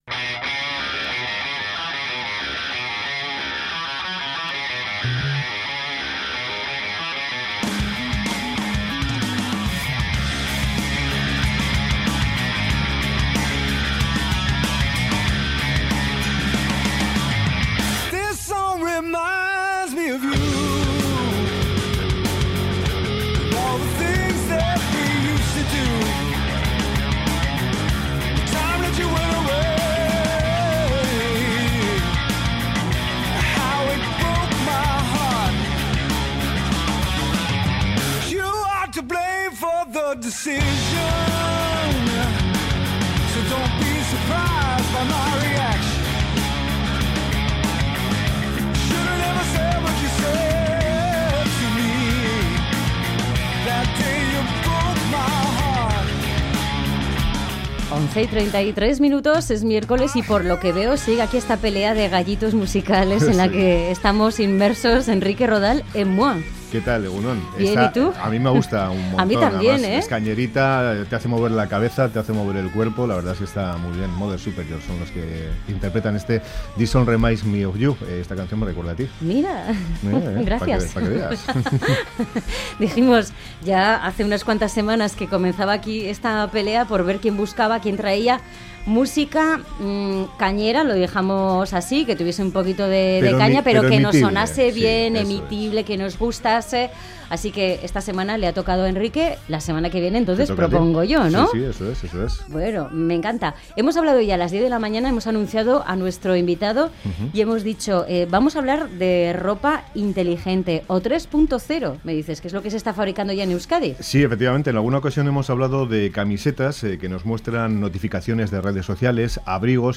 En Boulevard entrevistamos